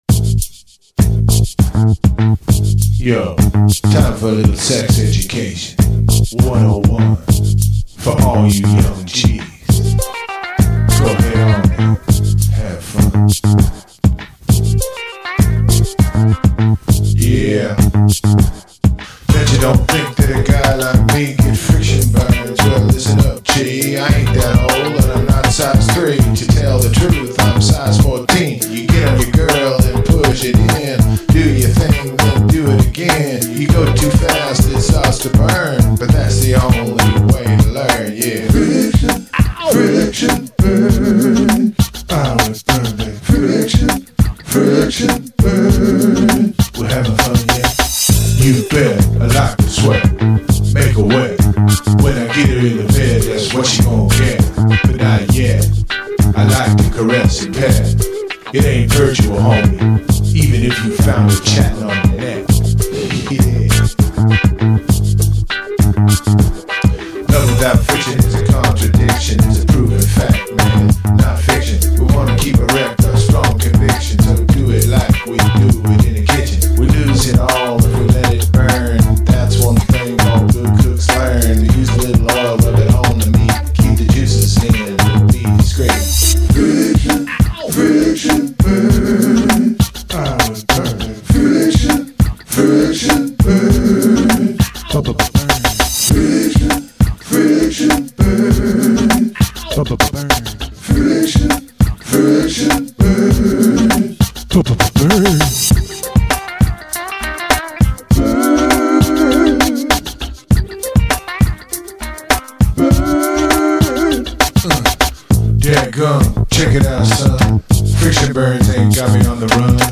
dance/electronic
House
Hip-hop
RnB